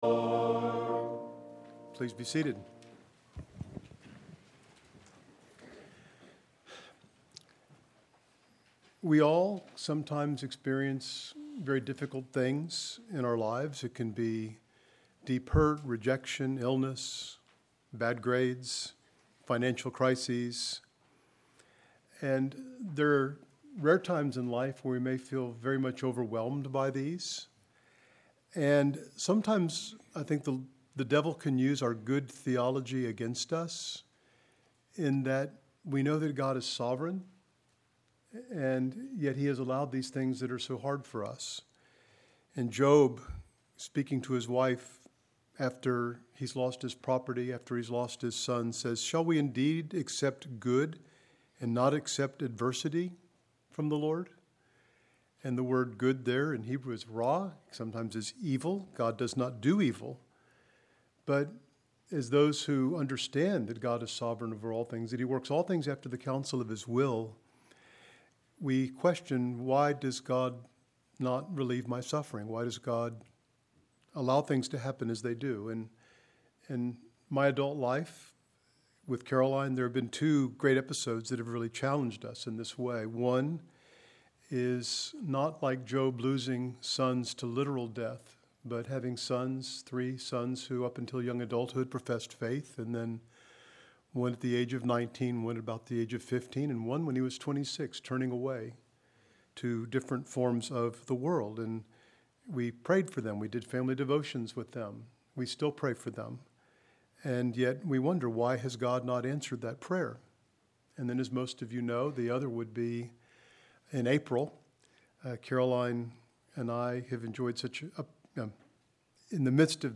RTS Charlotte Chapel Sermon – To Whom Shall We Go?